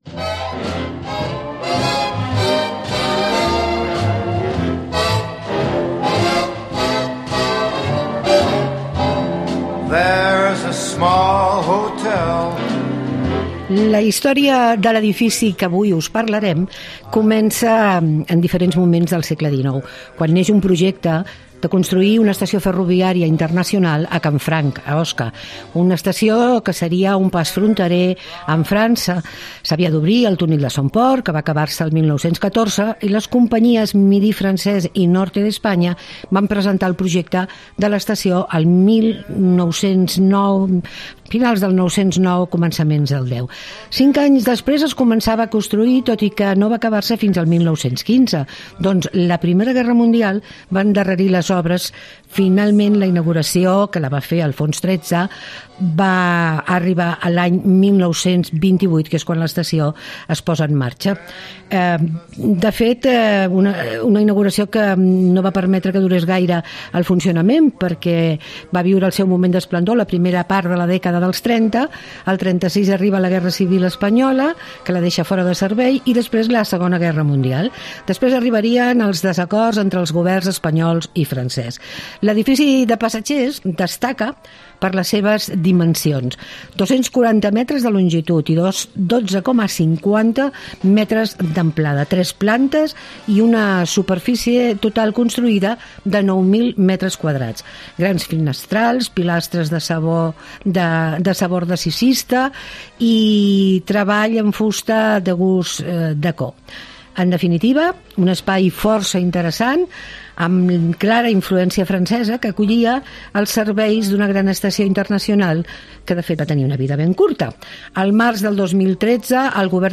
Todos los domingos hacemos una hora de radio pensada para aquellos que les gusta pasarlo bien en su tiempo de ocio ¿donde?